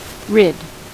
Ääntäminen
US : IPA : [ˈrɪd]